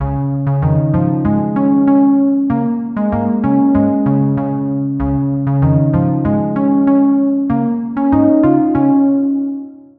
かわいくてシンプルなショートループできるジングル。